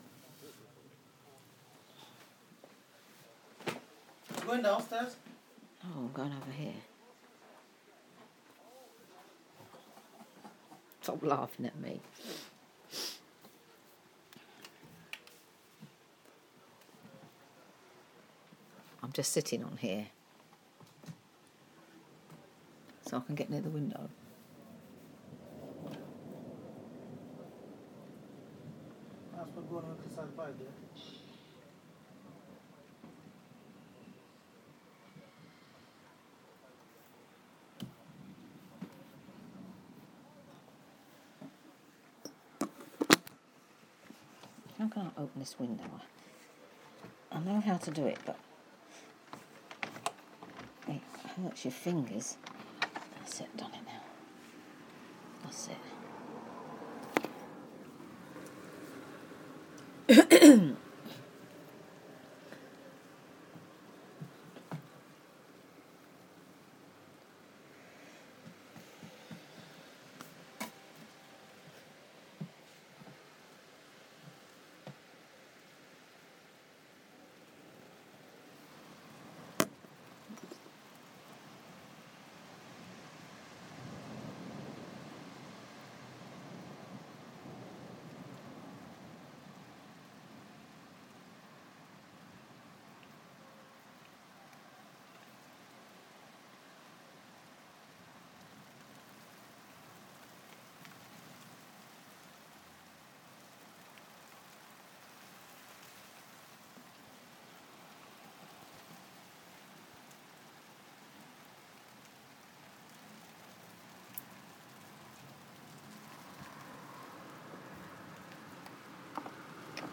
Some long awaited thunder!